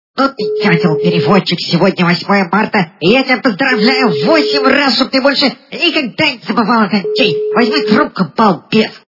» Звуки » Люди фразы » Голос - Сегодня 8 марта
При прослушивании Голос - Сегодня 8 марта качество понижено и присутствуют гудки.